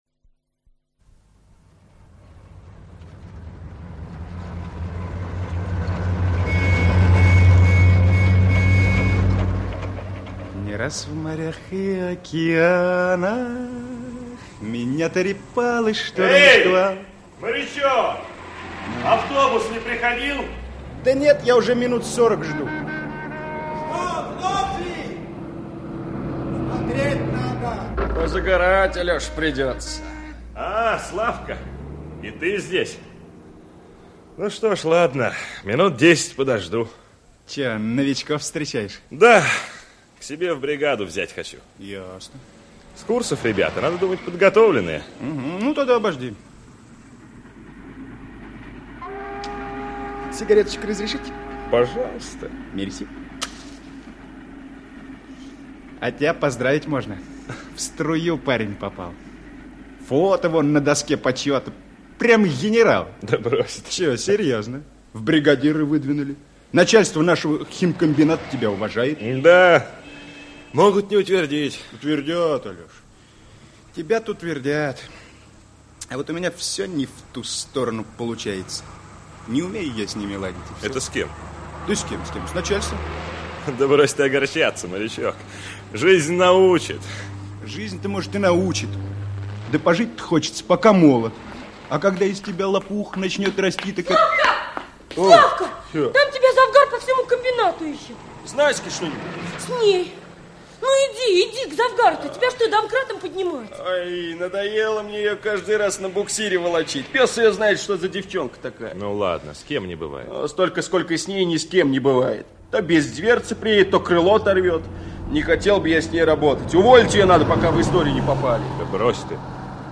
Аудиокнига Учусь думать | Библиотека аудиокниг